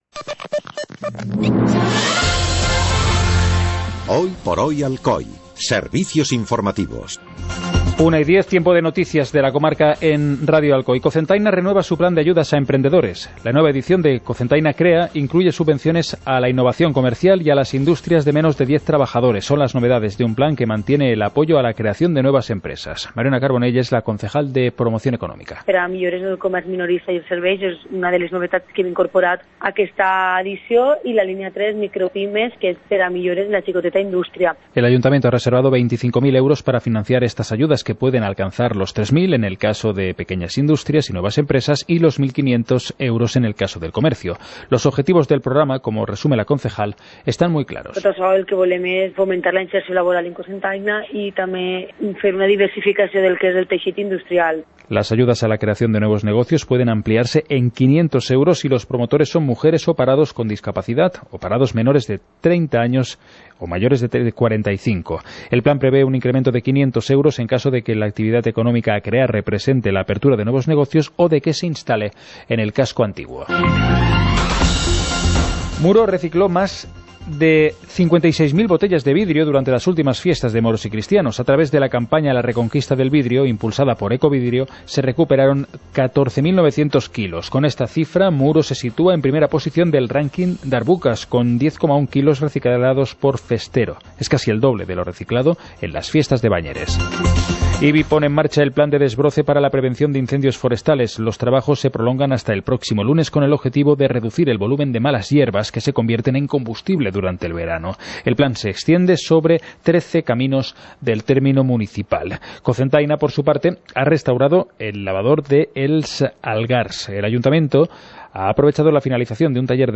Informativo comarcal - jueves, 28 de junio de 2018